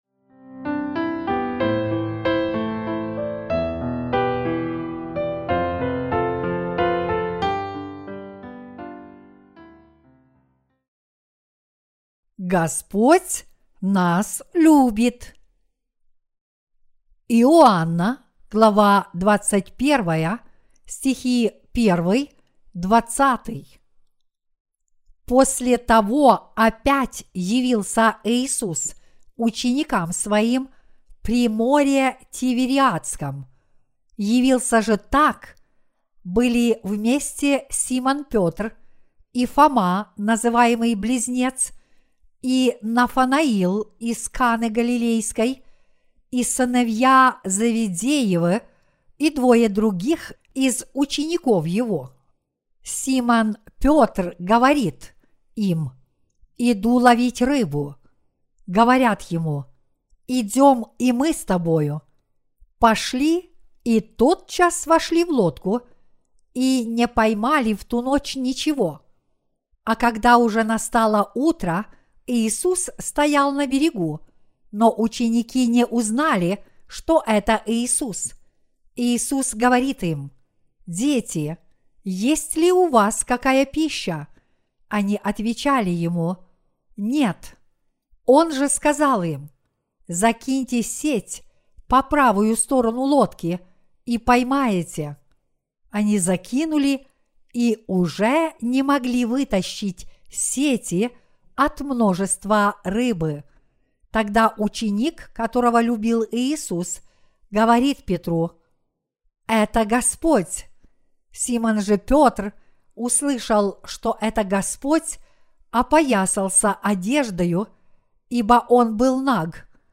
Проповеди о Евангелии от Иоанна (VIII) - Господь даровал нам благословенную жизнь 13.